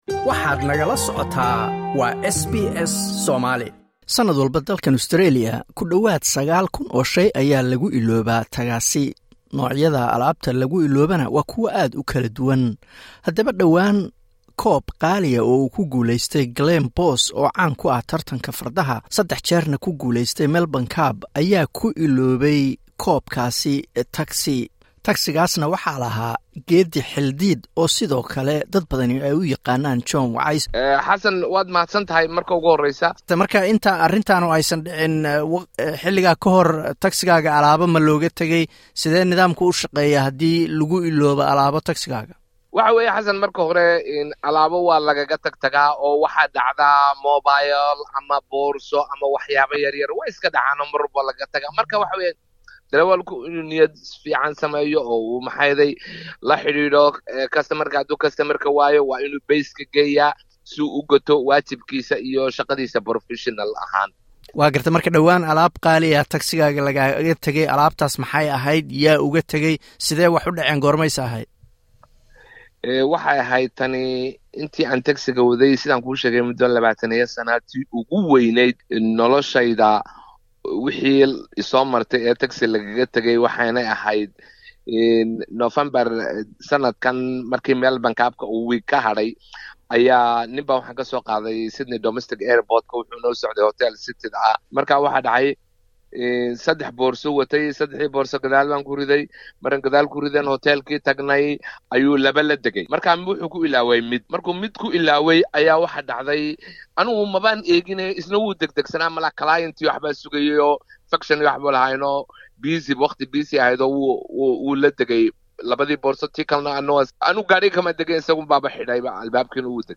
Taksiile Soomaali ah oo shay aad qaali u ah lagu iloobay taksigiisa ayaa ka waramaya sida arintu u dhacday.